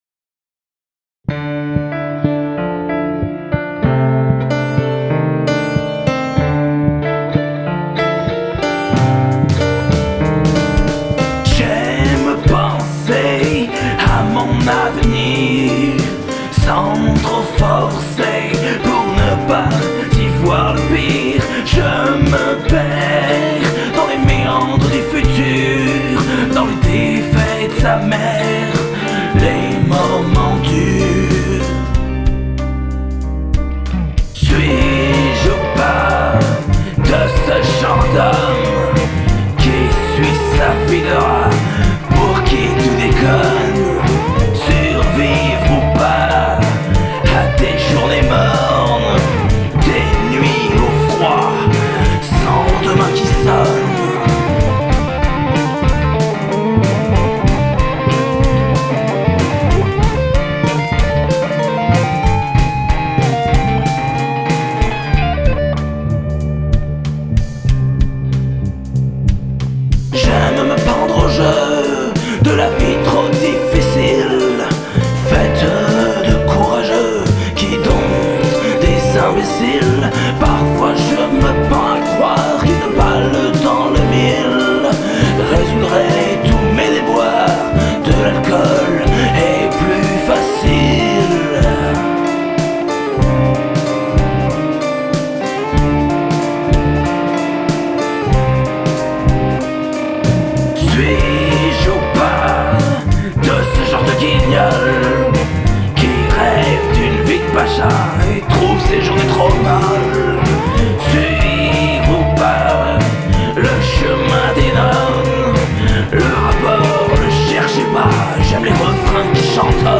un petit morceau qui est bien dans la mouvance blues de cette saison